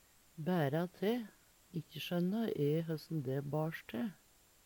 bæra te - Numedalsmål (en-US)